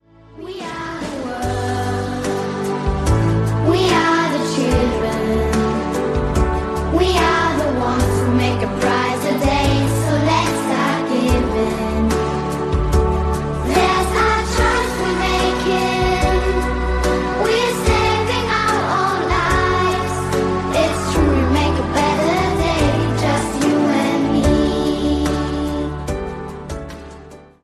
Coro De Voces Blancas Infantiles (audio/mpeg)
Coro de voces blancas infantiles